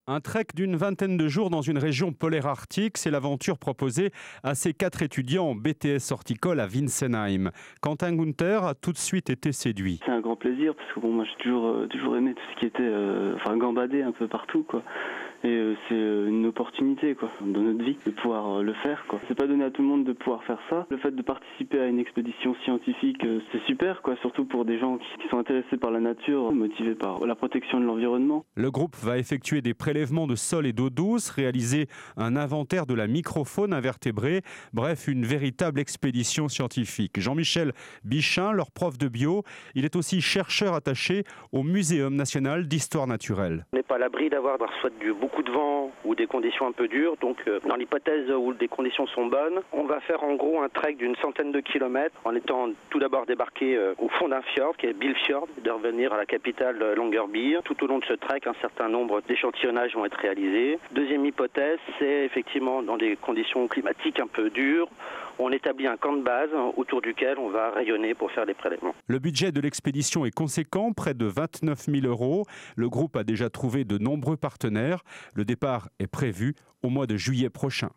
Flash info diffusé sur France Bleu Alsace les 25-26 déc. 2008